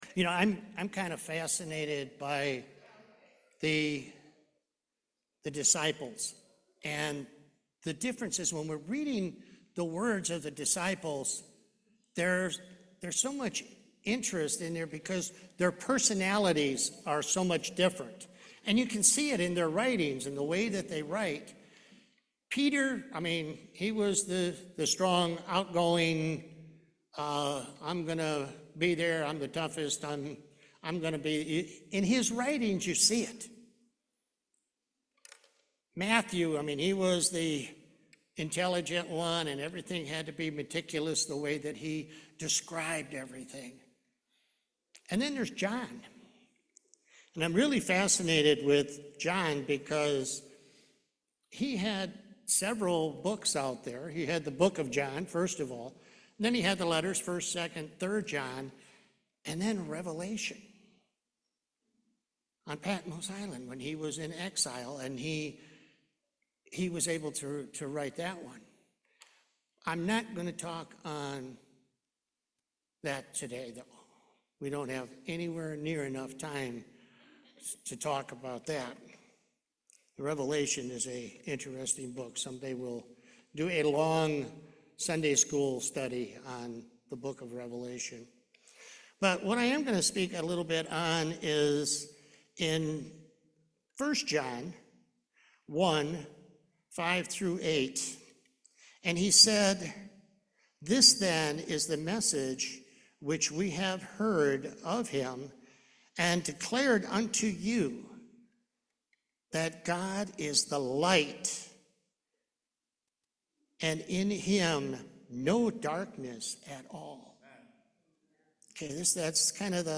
John 8:12 Service Type: Main Service He is the all encompassing light.